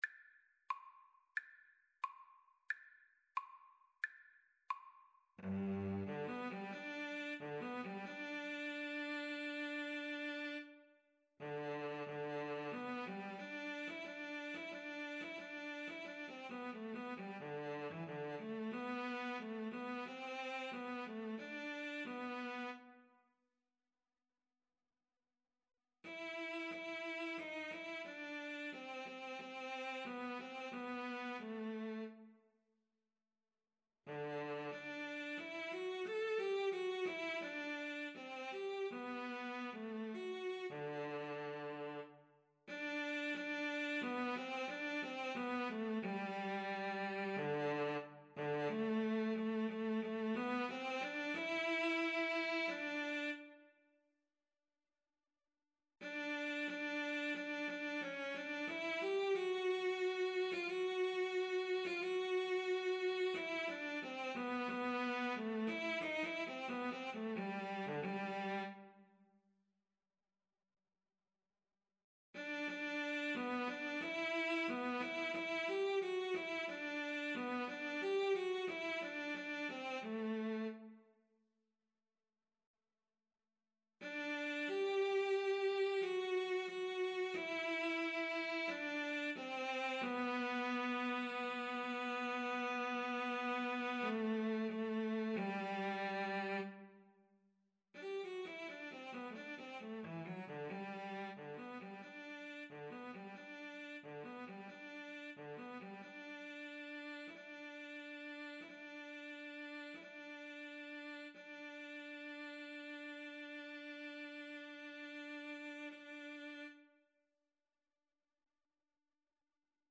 4/4 (View more 4/4 Music)
G major (Sounding Pitch) (View more G major Music for Cello Duet )
Moderato =90
Cello Duet  (View more Intermediate Cello Duet Music)
Classical (View more Classical Cello Duet Music)